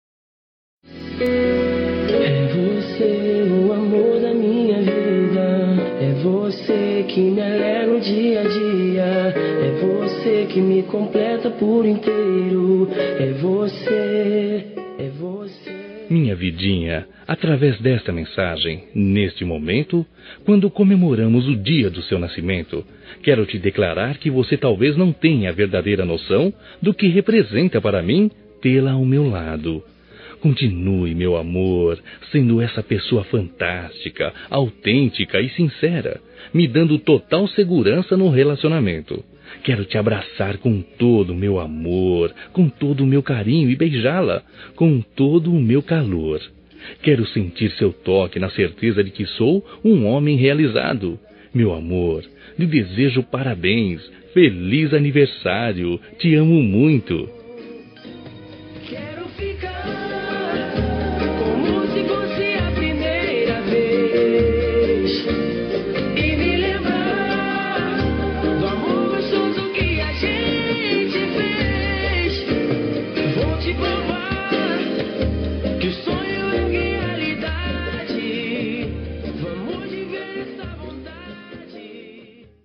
Telemensagem de Aniversário Romântico – Voz Masculina- Cód: 202128